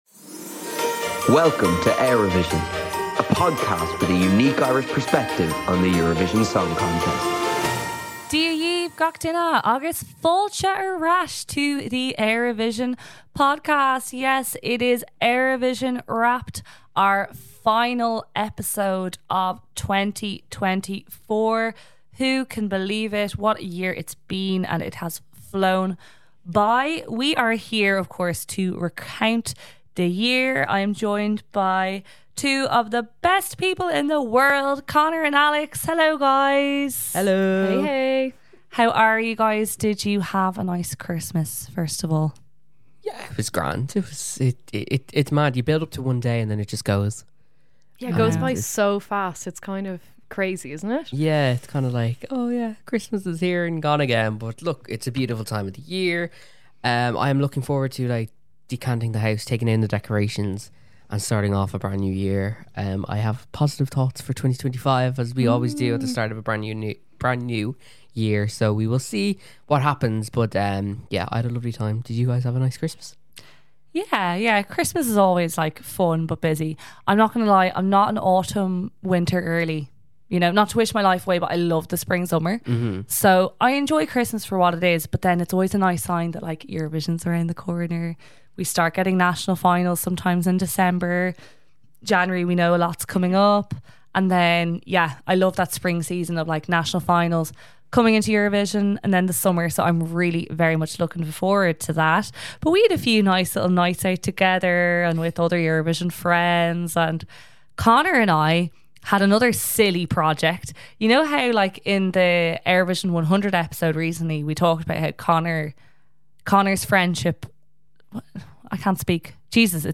We're sharing a few highlights from our live show too including a special performance by Mickey Joe Harte!